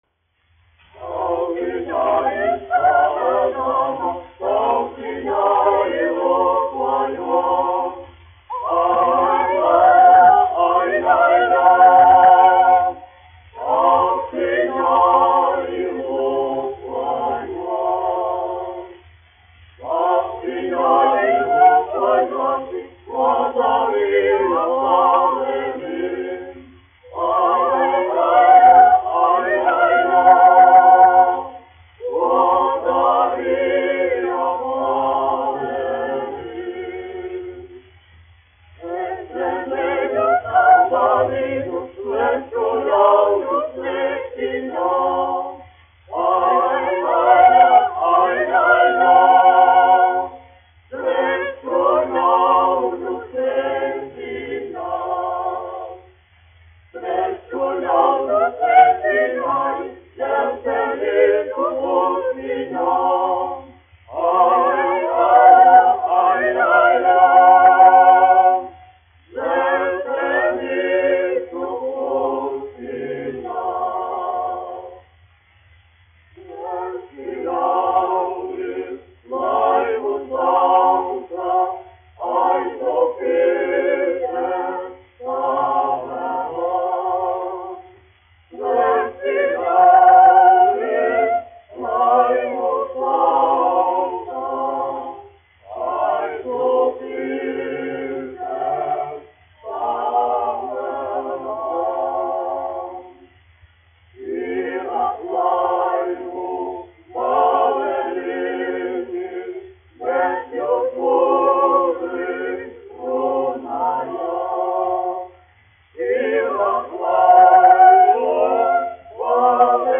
Latviešu izglītības biedrības koris, izpildītājs
1 skpl. : analogs, 78 apgr/min, mono ; 25 cm
Latviešu tautasdziesmas
Kori (jauktie)
Skaņuplate